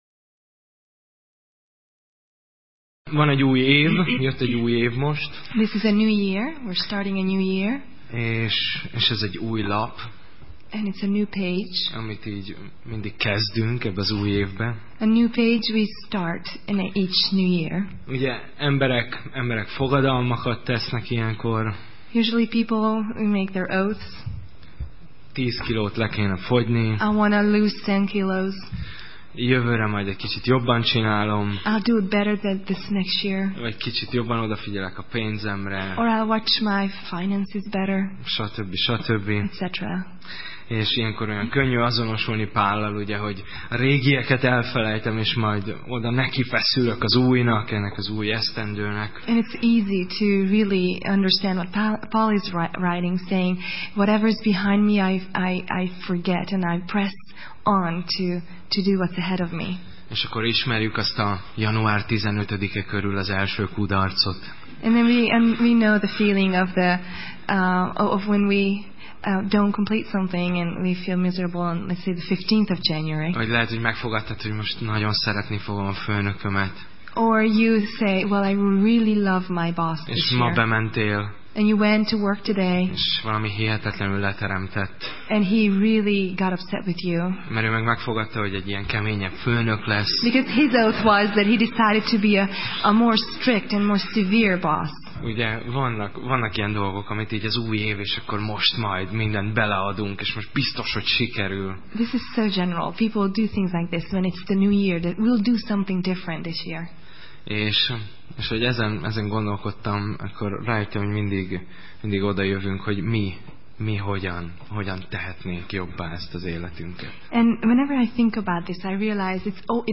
Tematikus tanítás
Alkalom: Szerda Este